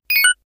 powerUp5.ogg